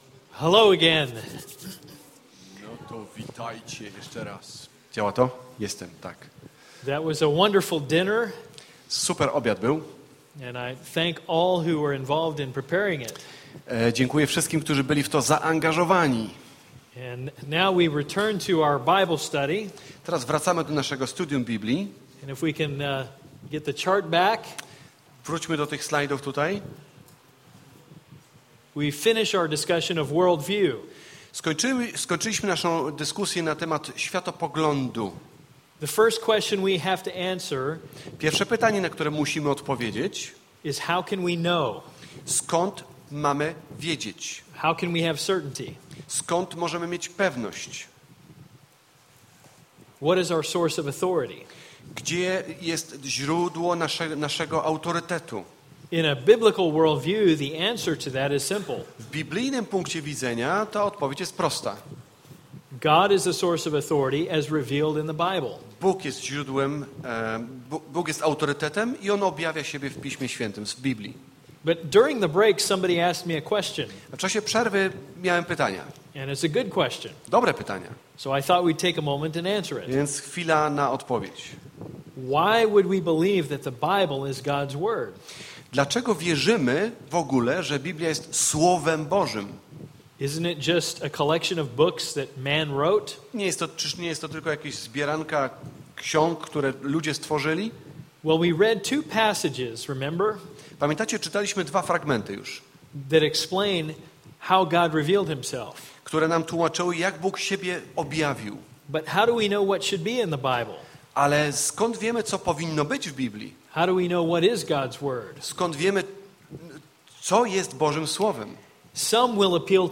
Wykład 2: Przeszkody w studiowaniu